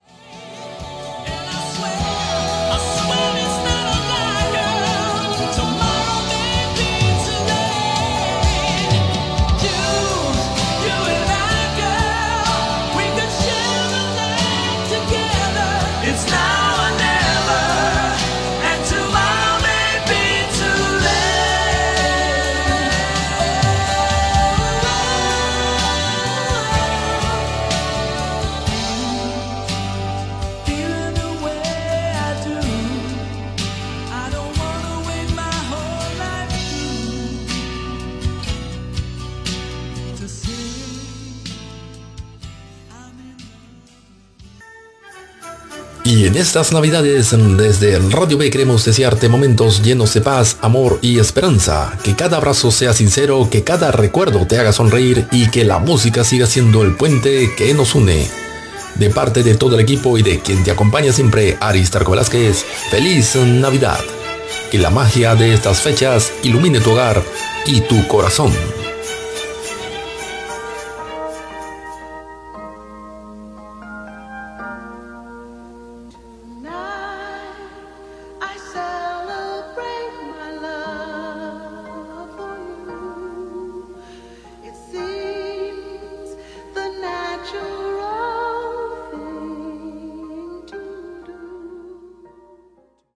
Tema musical, desig de bon Nadal i tema musical.
Musical